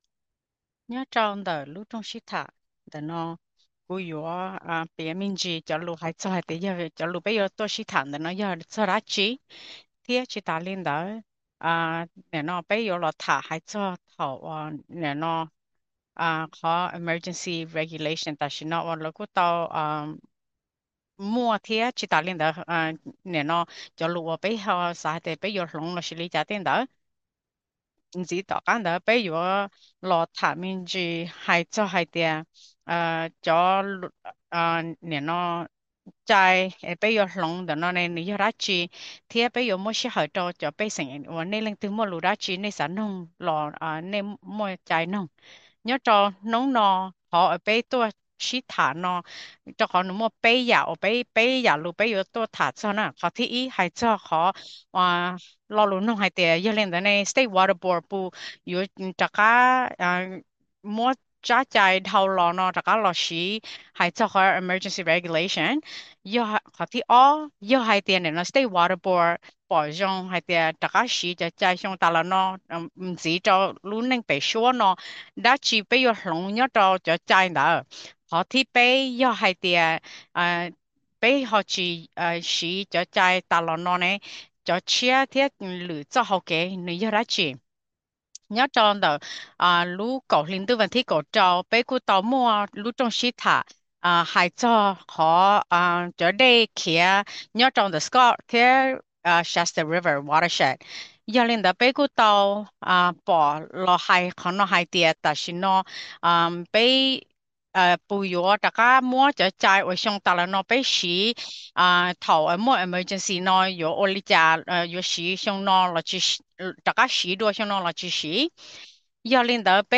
Hmong Language Interpretation
scott-shasta-2024-public-meeting-hmong-audio.mp3